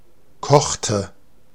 Ääntäminen
Ääntäminen Tuntematon aksentti: IPA: /ˈkɔχtə/ Haettu sana löytyi näillä lähdekielillä: saksa Käännöksiä ei löytynyt valitulle kohdekielelle. Kochte on sanan kochen imperfekti.